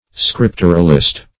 Search Result for " scripturalist" : The Collaborative International Dictionary of English v.0.48: Scripturalist \Scrip"tur*al*ist\, n. One who adheres literally to the Scriptures.